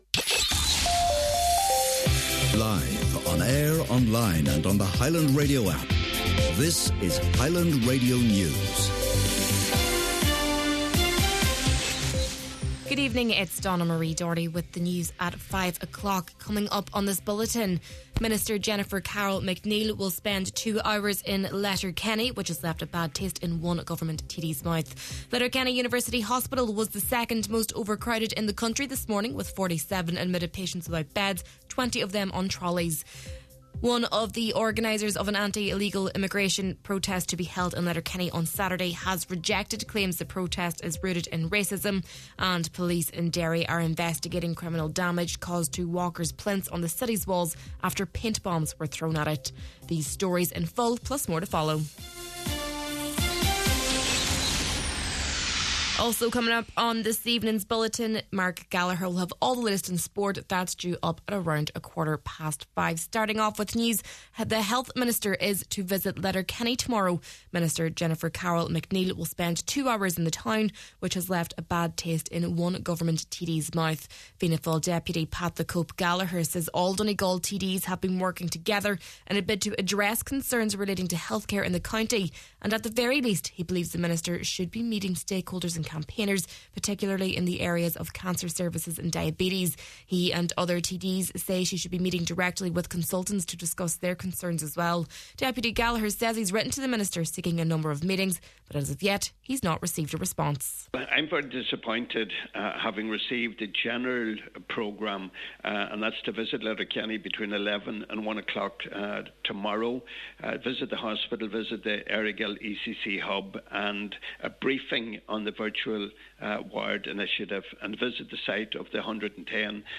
Main Evening News, Sport and Obituaries – Wednesday, April 30th